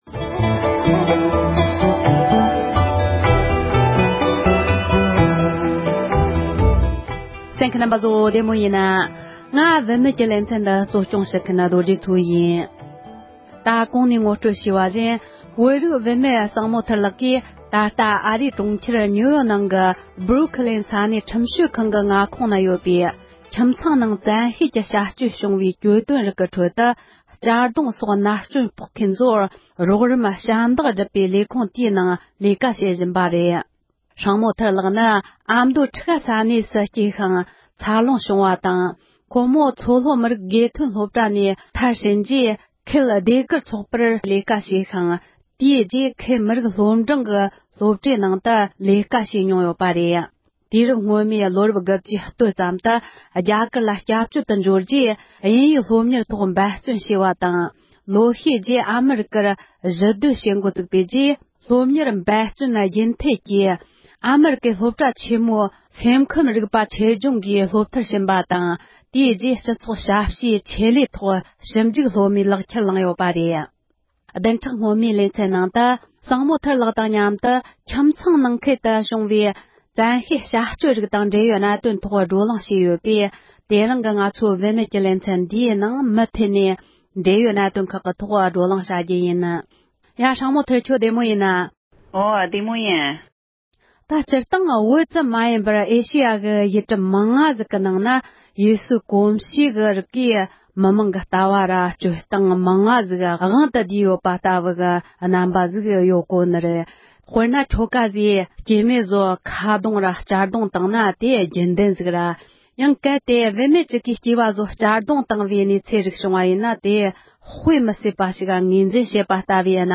གླེང་མོལ་ཞུས་པའི་དུམ་བུ་གཉིས་པ་འདི་གསན་རོགས༎